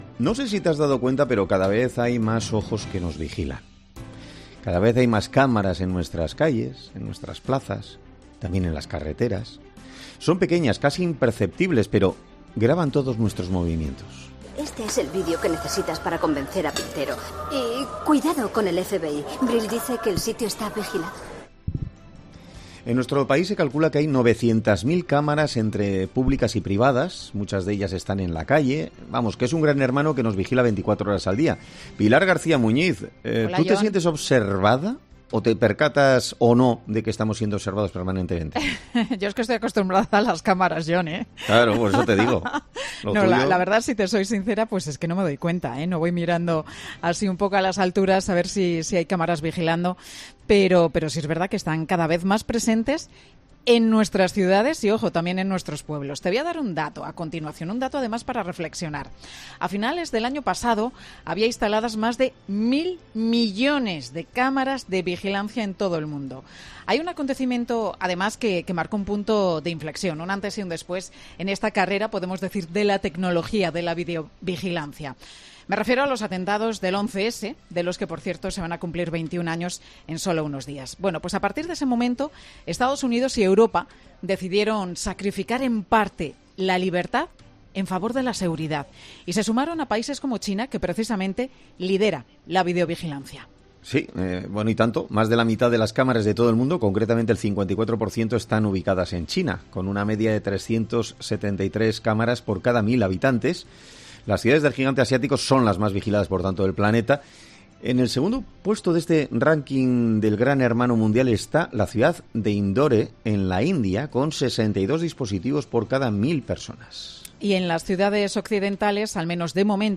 abogado experto en protección de datos